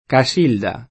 vai all'elenco alfabetico delle voci ingrandisci il carattere 100% rimpicciolisci il carattere stampa invia tramite posta elettronica codividi su Facebook Casilda [sp. ka S& lda ; it. ka S& lda ] pers. f. — in it., anche Casilde [ ka S& lde ]